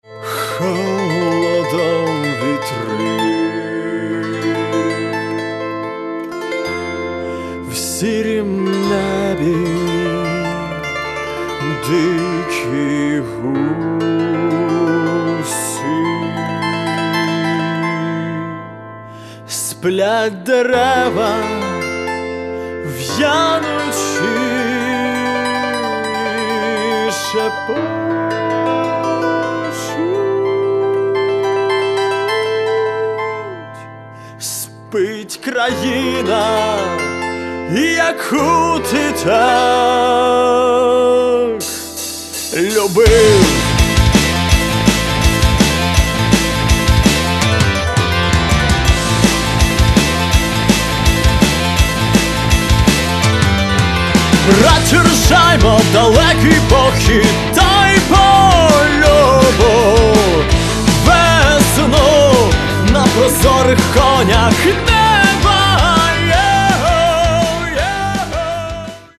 Каталог -> Рок и альтернатива -> Энергичный рок